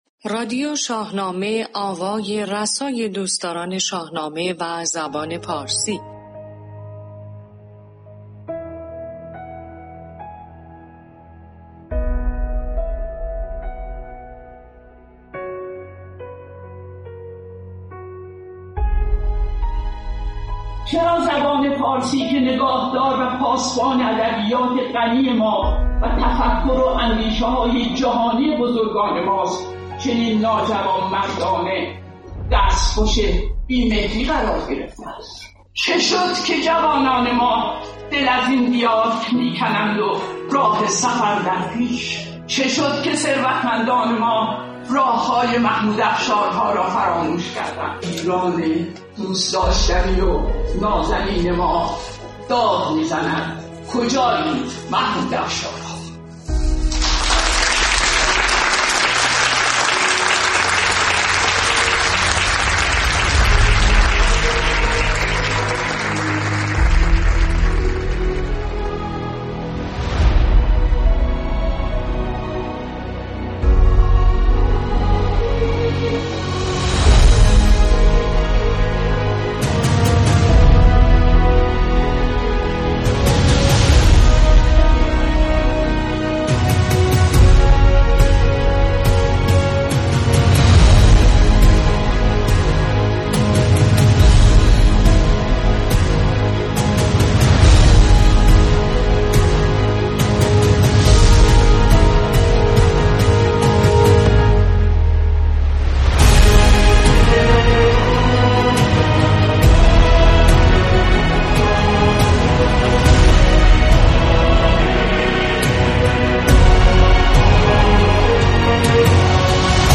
رادیو شاهنامه یک پادکست در حوزه شاهنامه است که در استودیو باشگاه شاهنامه پژوهان ، ویکی شاهنامه افتخار دارد با کسب اجازه از باشگاه شاهنامه پژوهان و با ذکر منبع ، بدون هیچ تغییری در رادیو شاهنامه ، بخش های مختلف آن را برای استفاده شاهنامه دوستان بصورت رایگان قرار دهد.